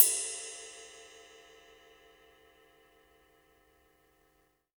D2 RIDE-01.wav